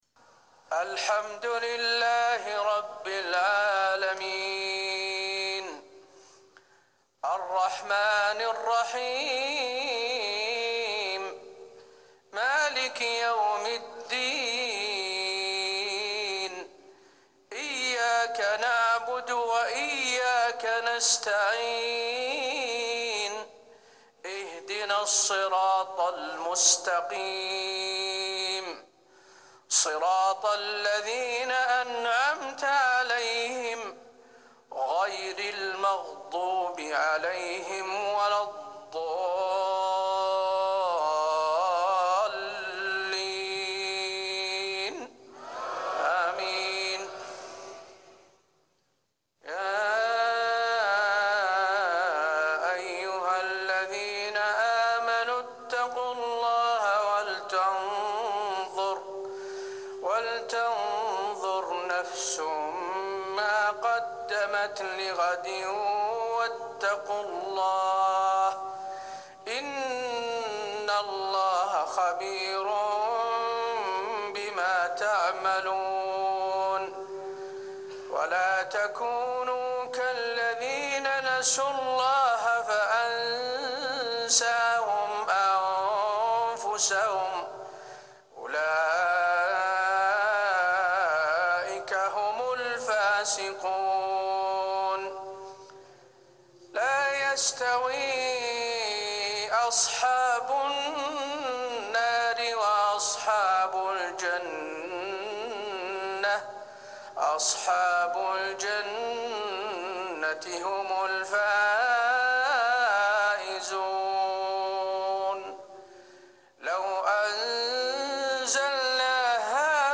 صلاة العشاء 1-6-1440هـ خواتيم سورة الحشر 18-24 | isha 6-2-2019 prayer from Surat Al-Hashr > 1440 🕌 > الفروض - تلاوات الحرمين